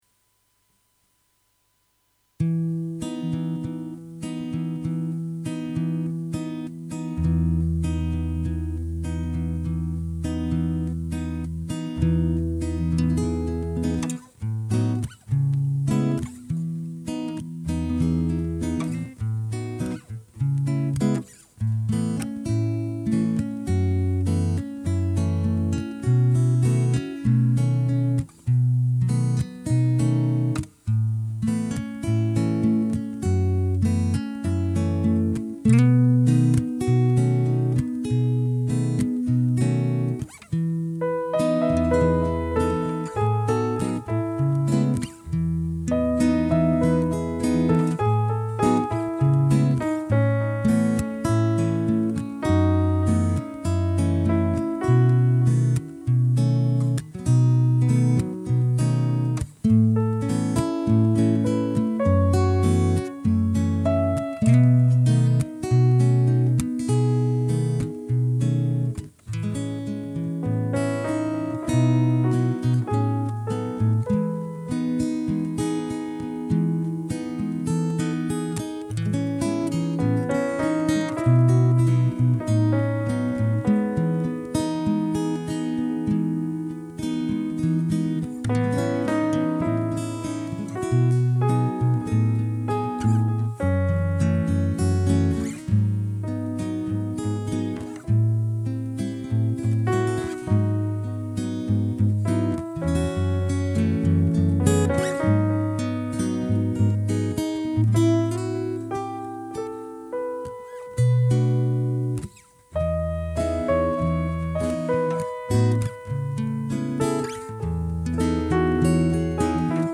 El piano es para meter una línea melódica pero supongo que la cambiaré o mejoraré porque se trata de algo provisional.